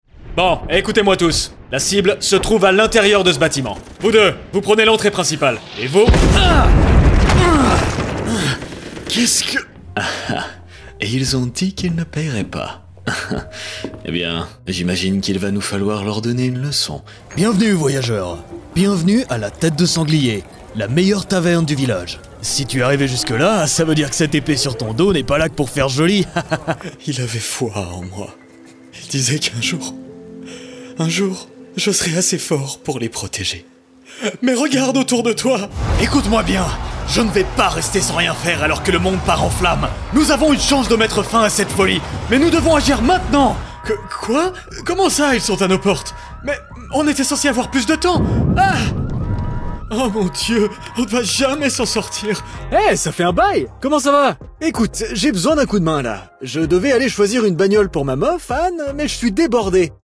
Male
Assured, Authoritative, Character, Confident, Cool, Corporate, Engaging, Friendly, Gravitas, Natural, Reassuring, Smooth, Warm, Versatile, Soft
Microphone: Audio Technica AT2020 USB+
Audio equipment: Sound-treated booth, Beyerdynamic DT250 headphones, SourceConnect Now, ipDTL, Skype